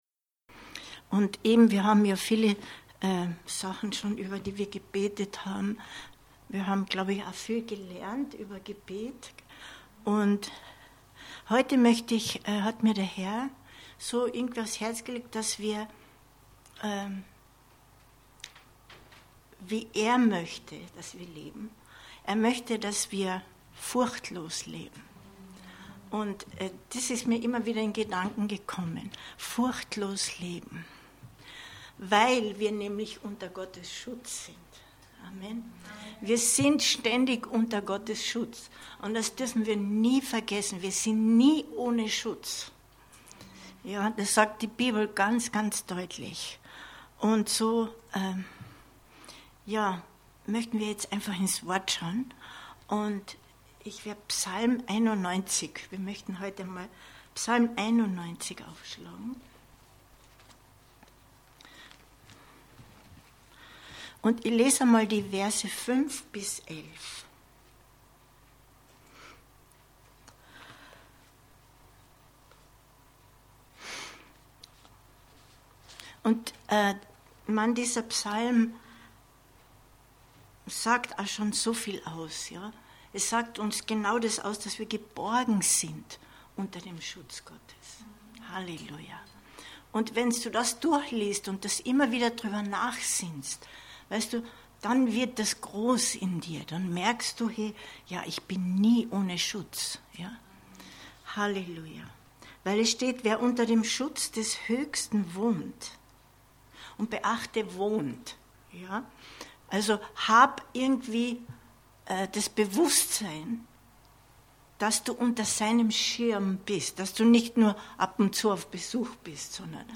Furchtlos leben 12.10.2022 Predigt herunterladen